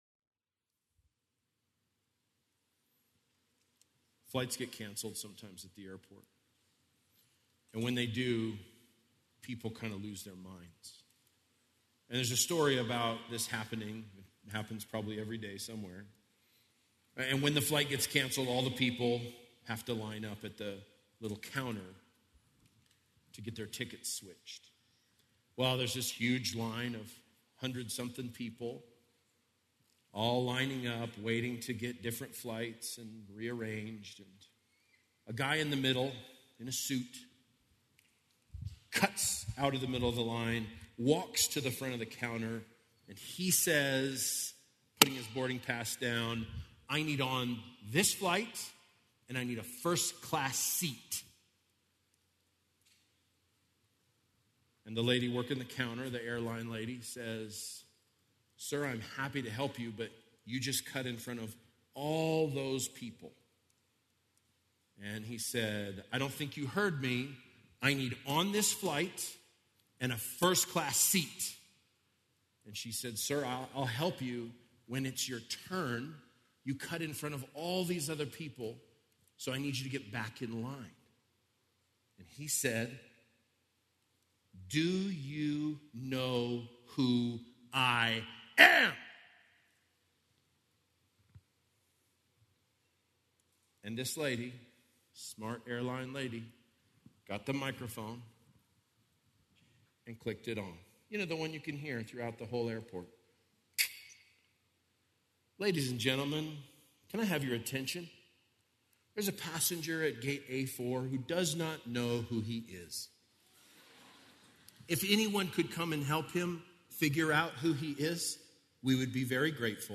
Due to technical difficulties this sermon is incomplete.